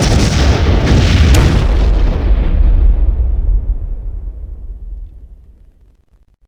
cargoBayDamage.wav